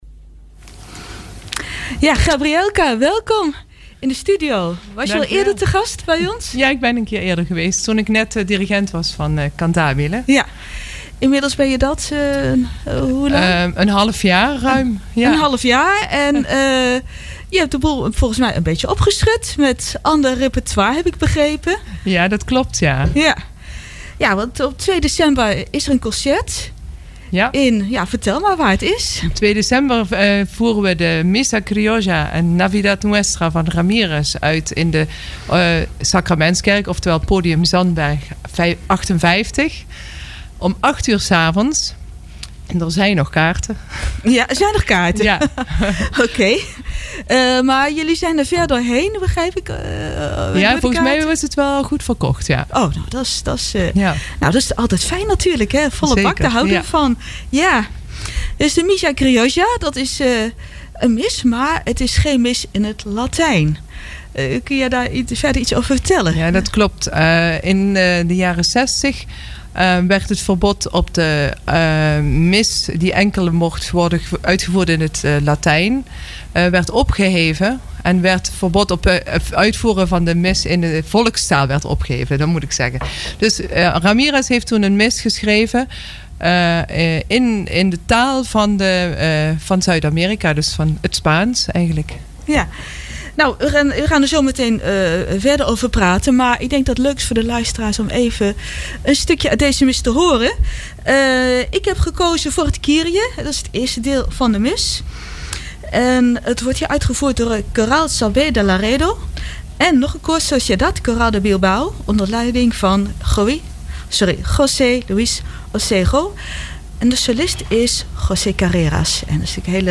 Gemengd koor Breda